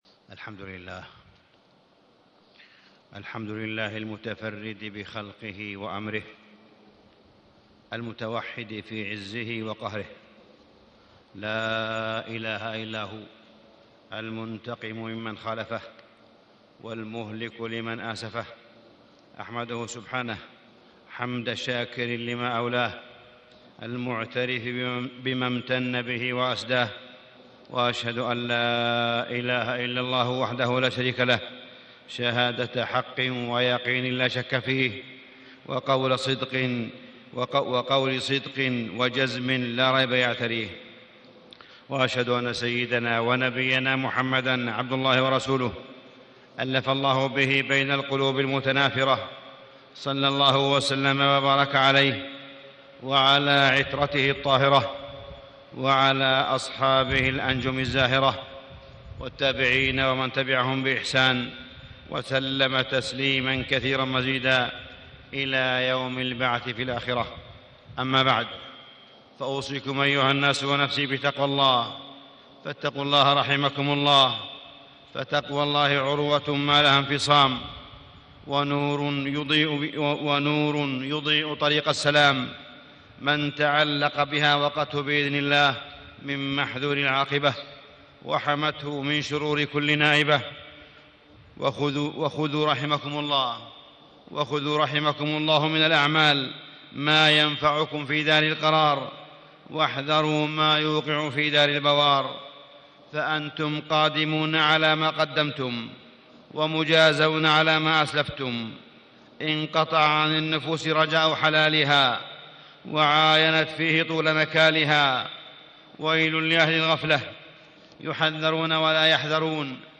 تاريخ النشر ١٢ رجب ١٤٣٦ هـ المكان: المسجد الحرام الشيخ: معالي الشيخ أ.د. صالح بن عبدالله بن حميد معالي الشيخ أ.د. صالح بن عبدالله بن حميد أهداف عاصفة الحزم